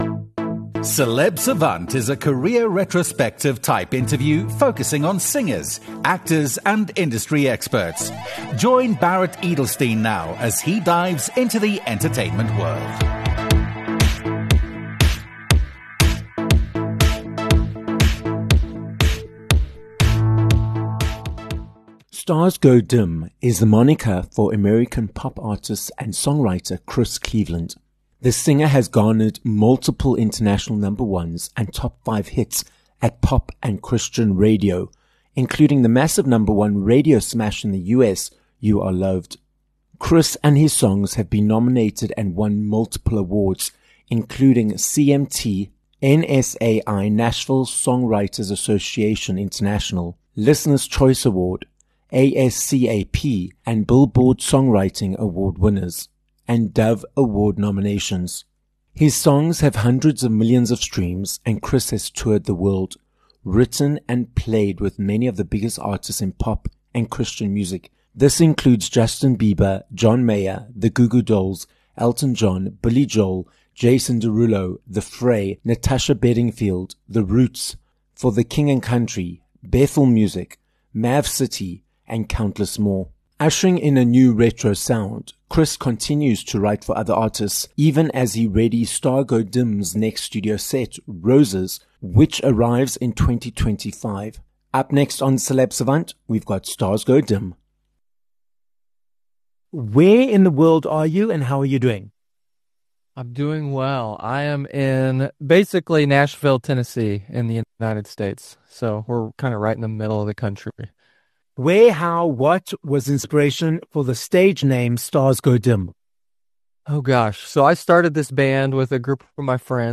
Stars Go Dim (Chris Cleveland) - American Christian and pop singer and songwriter - joins us on this episode of Celeb Savant. Chris takes us through his multiple award-winning (CMT, ASCAP, NSAI etc.) journey, which includes focusing on positivity during times of failure, the way he creates music, and about his latest album, Roses.